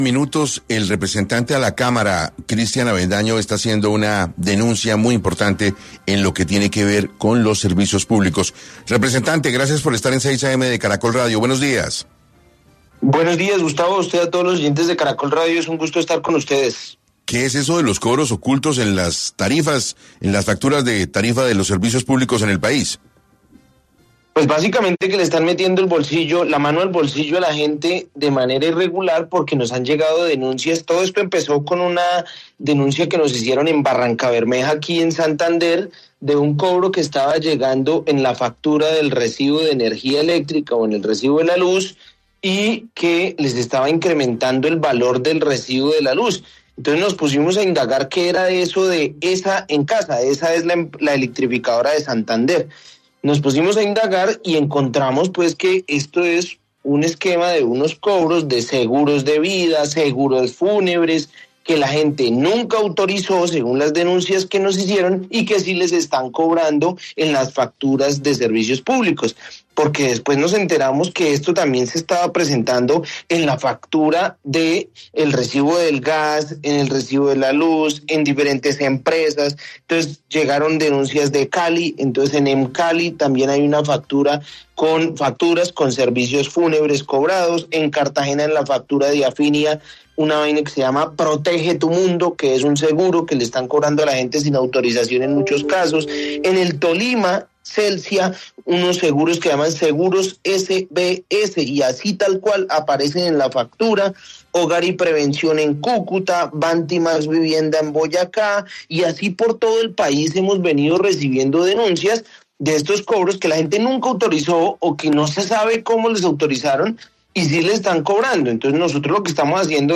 Cristian Avendaño, representante a la Cámara por Santander, denuncia en 6AM el incremento en el valor a los recibos públicos en Cali, Cartagena, Tolima, Cúcuta, Boyacá, etc.